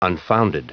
Prononciation du mot unfounded en anglais (fichier audio)
Prononciation du mot : unfounded